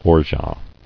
[or·geat]